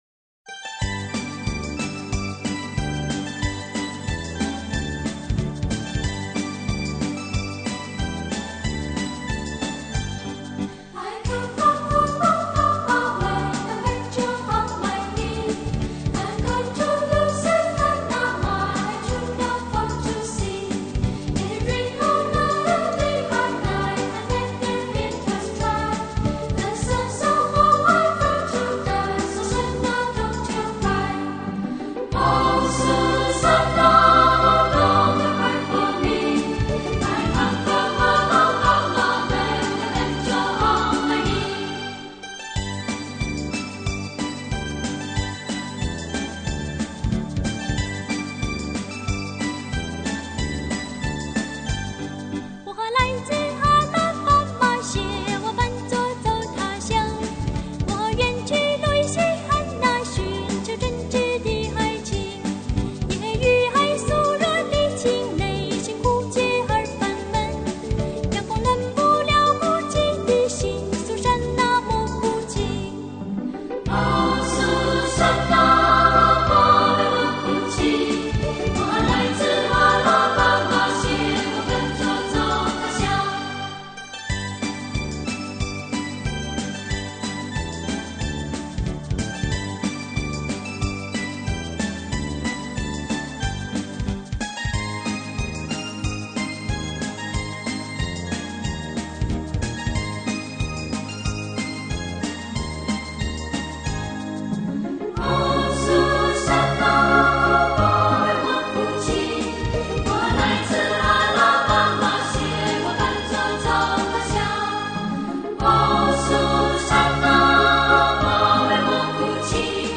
在蘭陽平原的稻香迴廊中，聽見純淨的天使聲音穿越畝畝田地.
這是一張童趣十足、音樂性佳、選曲多元化的兒童專輯，
豐富、活潑的音樂內容絕對適合親子共同聆聽！